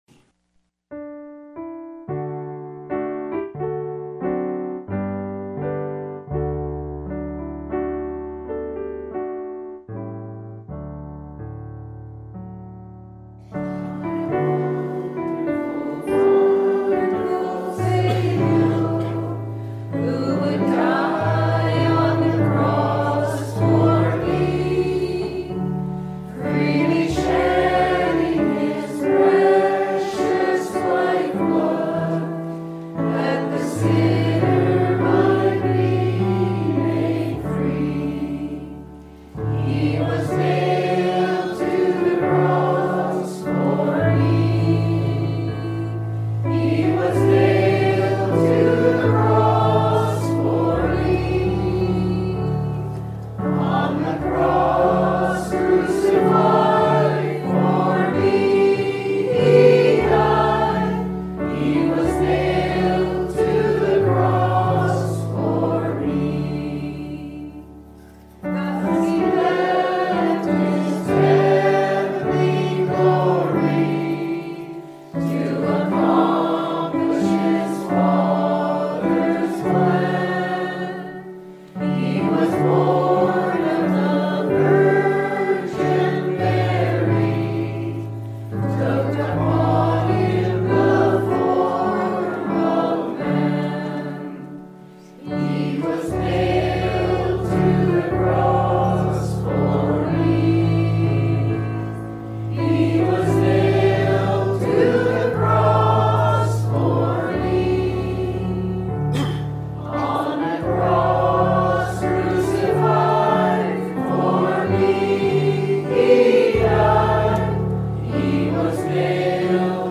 Service Type: Easter